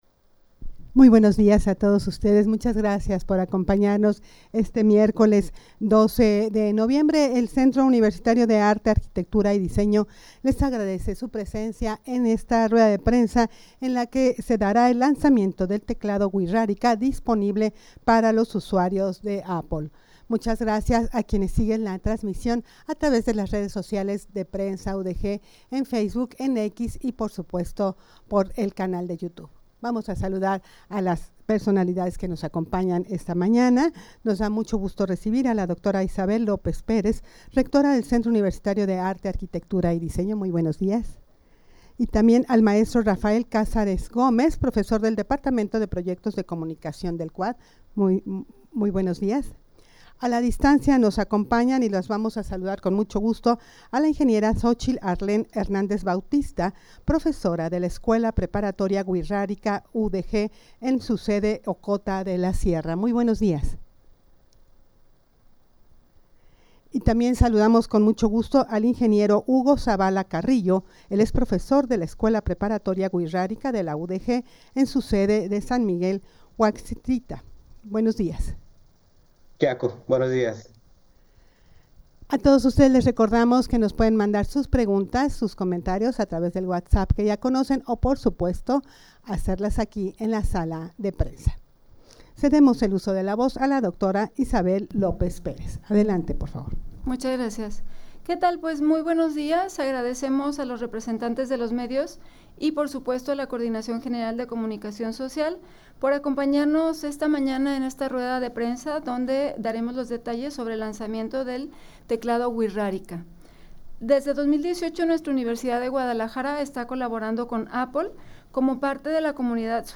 rueda-de-prensa-lanzamiento-del-teclado-wixarika-disponible-para-los-usuarios-de-apple.mp3